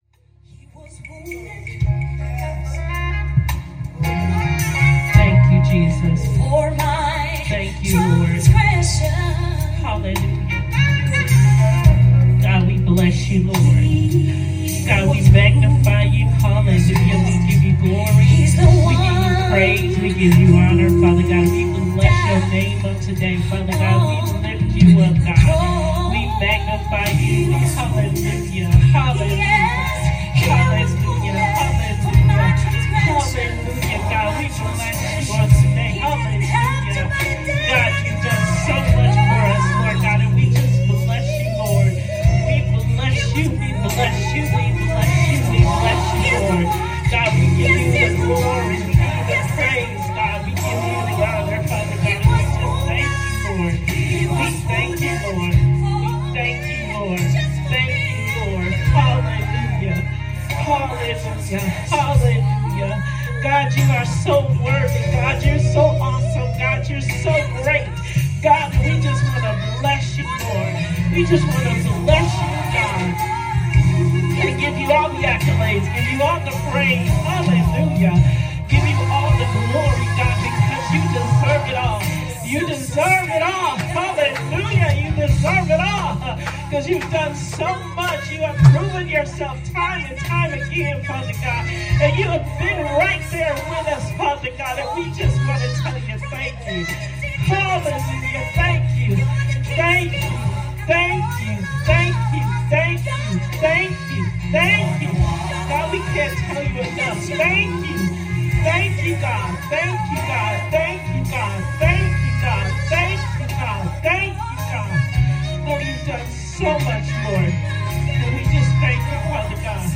Prayer